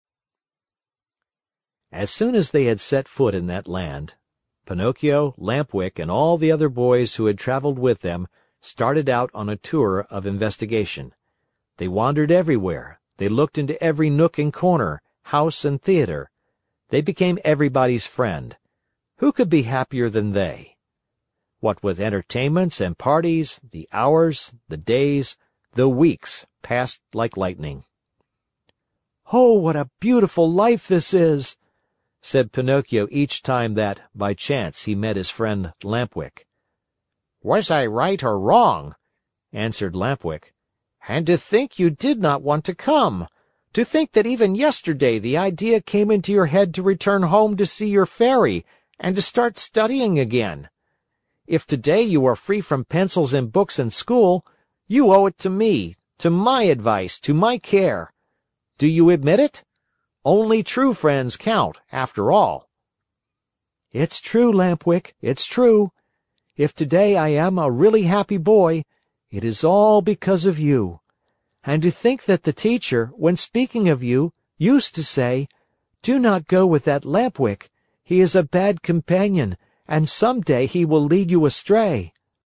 在线英语听力室木偶奇遇记 第118期:在玩具国享乐(9)的听力文件下载,《木偶奇遇记》是双语童话故事的有声读物，包含中英字幕以及英语听力MP3,是听故事学英语的极好素材。